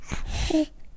byawn1.ogg